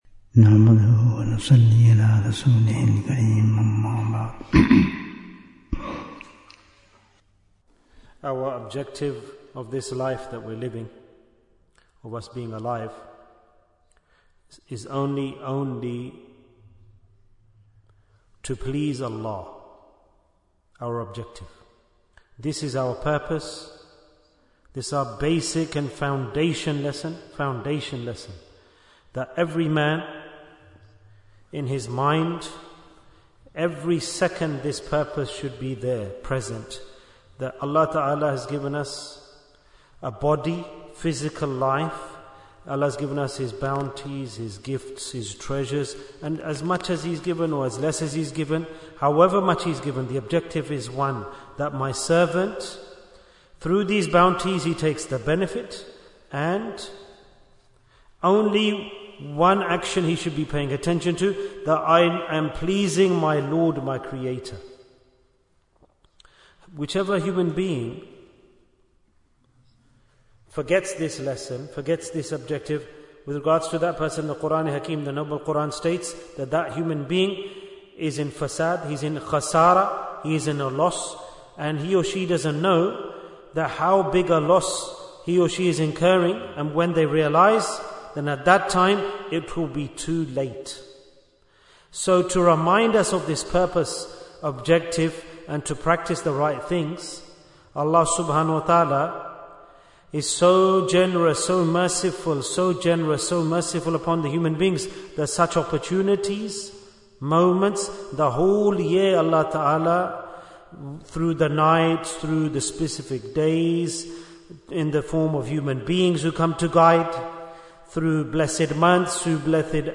Jewels of Ramadhan 2025 - Episode 3 Bayan, 27 minutes3rd March, 2025